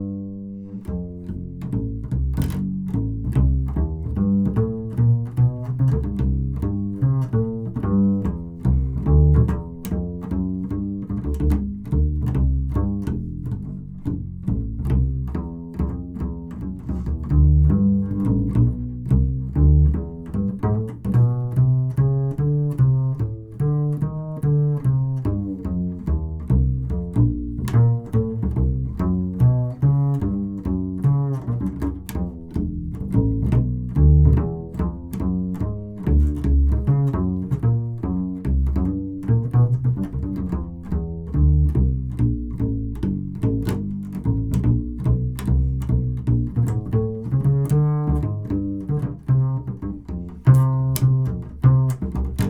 To my ears the NOS Telefunken tube makes the mic more mid-focused, the low frequencies are still clear, warm and big, the highs are a little less bright and sharp but the mid presence makes the mic cut through the mix more.
All clips were recorded via the preamps on my UA Apollo interface, I’ve endeavoured to maintain the same conditions and distances between mic and source for each comparison, but there may be small variations.
Double Bass
NOS Telefunken E88CC
rode-k2-nos-telefunken-dbl-bass.wav